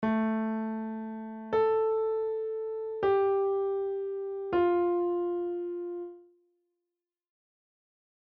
Here's another example, this time with A and A
then two different white notes